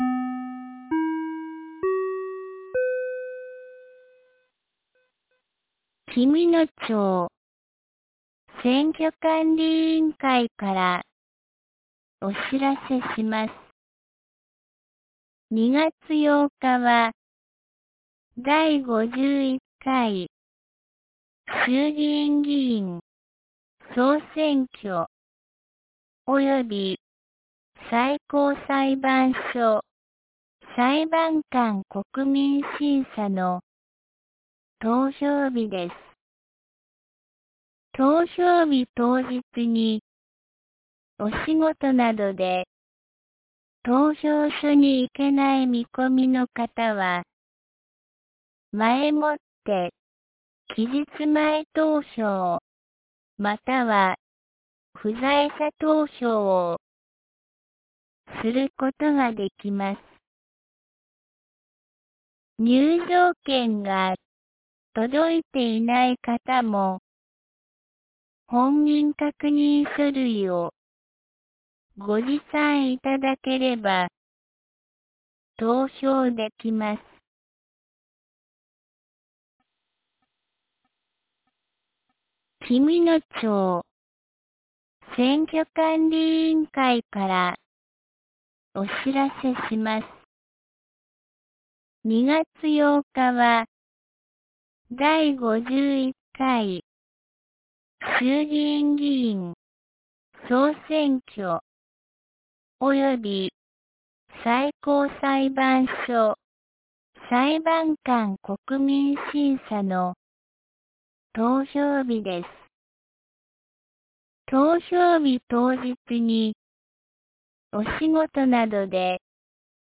2026年02月03日 17時07分に、紀美野町より全地区へ放送がありました。